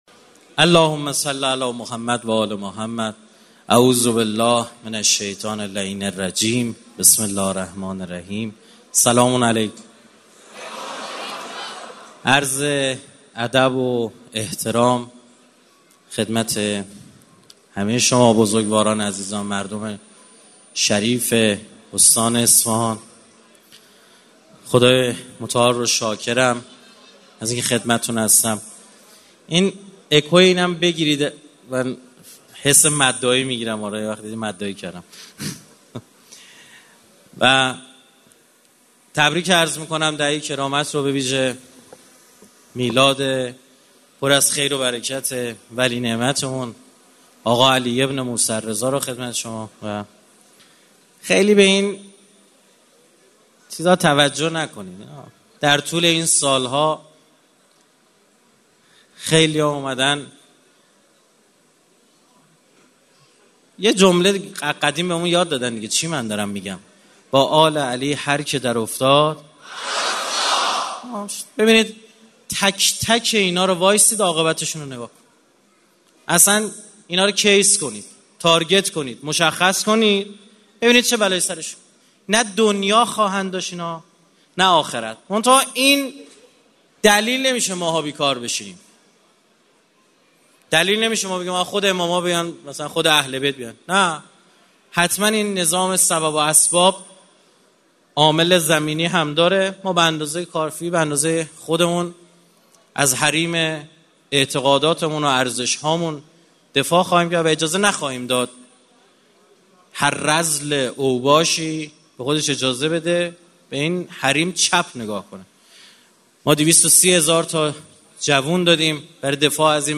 دانلود سخنرانی رائفی پور مشکلات اقتصادی دولت رئیسی – حتما گوش کنید!!!